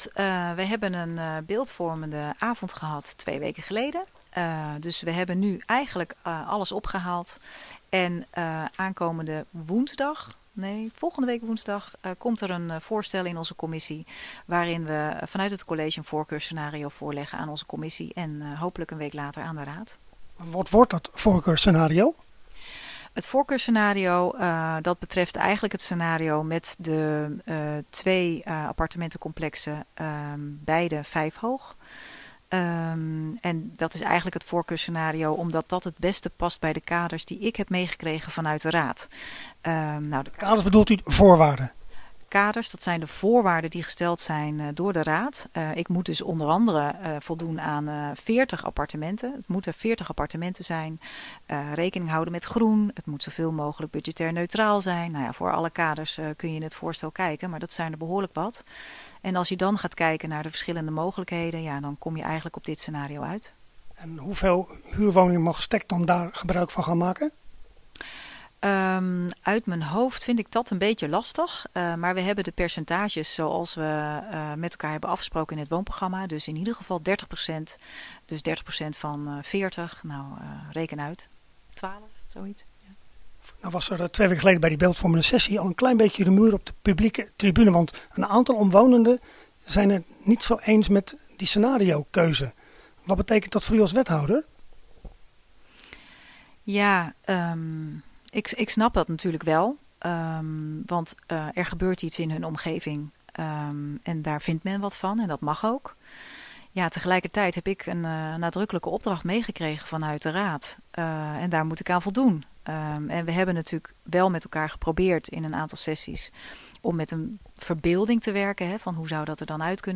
Hieronder het radio-interview: